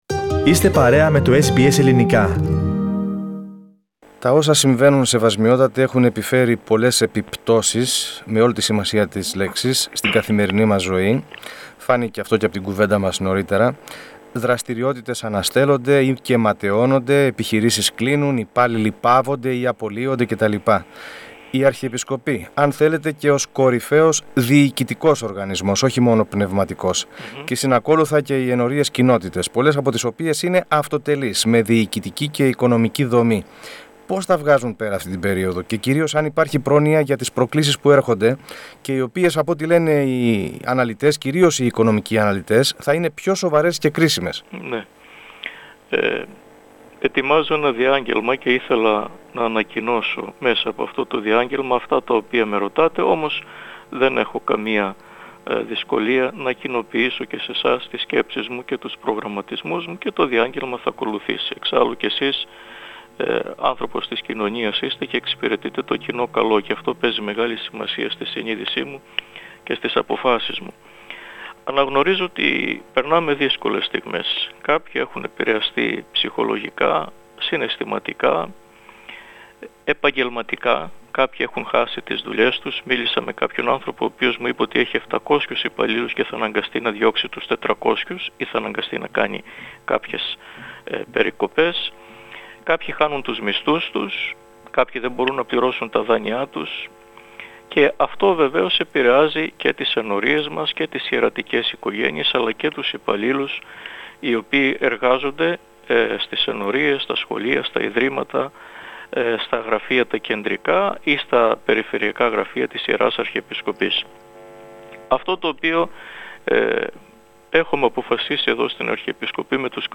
Speaking exclusively to SBS Greek, his Eminence said that the Parishes-Communities will be assisted in financial stress there are having due to the coronavirus. Furthermore, he said that he has received assurances from the relevant authorities that the clergy will be receive benefits that have been announced by the Federal government.